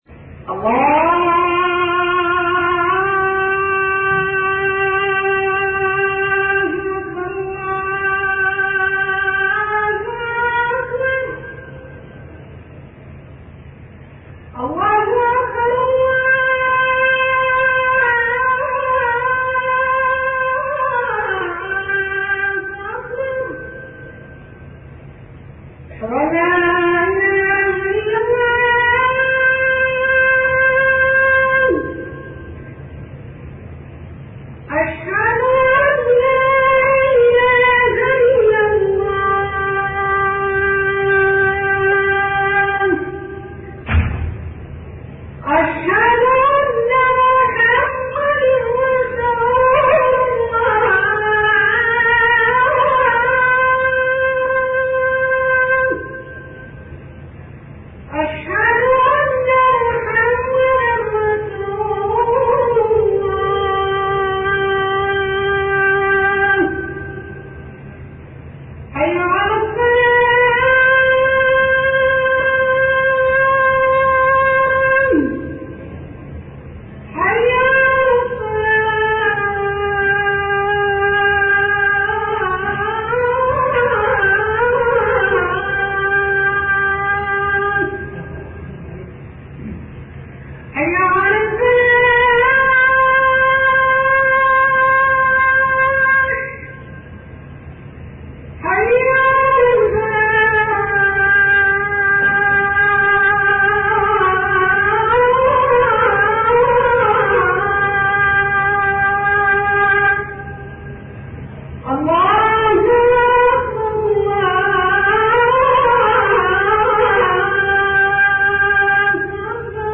أناشيد ونغمات
عنوان المادة أذان-8